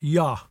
[yeeAAHW]